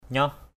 /ɲɔh/ (đg.) giao cấu, giao hợp = coïter, forniquer. to fuck, fornicate, copulate.